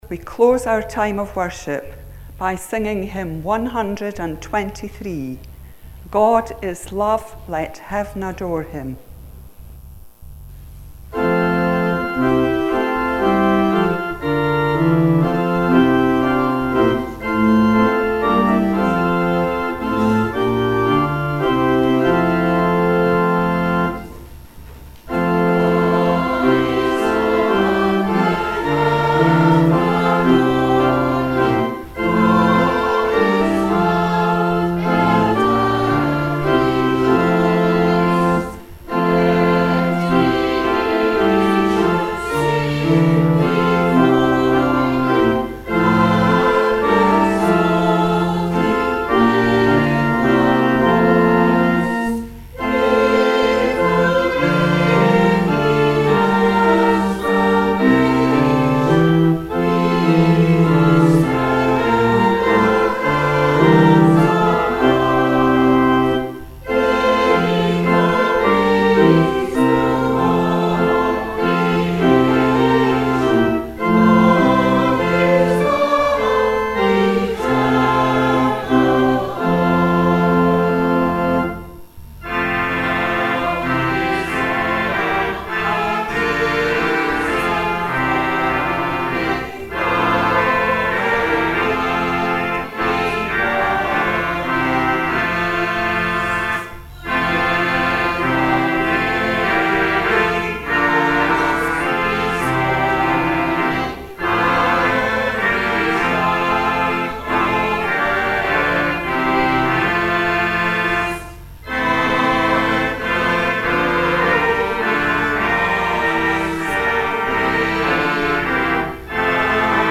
Our final praise for the service was